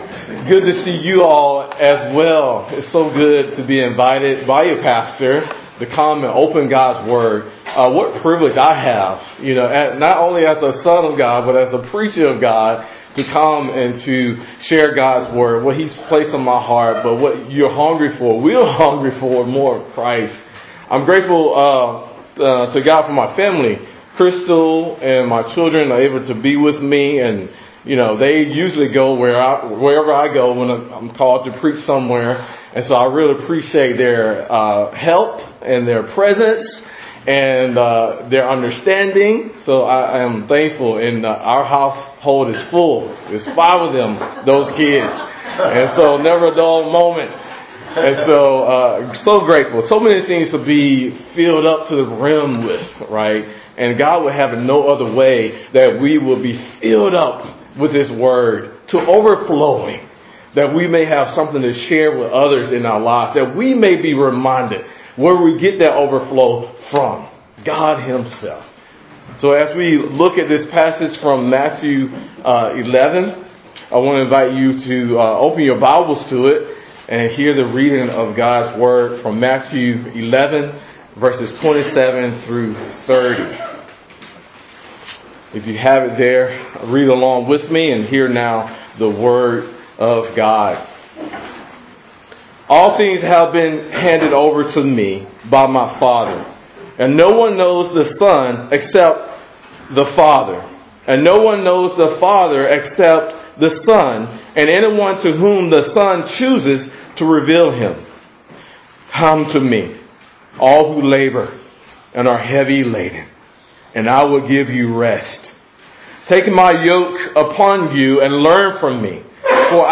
2_23_25_ENG_Sermon.mp3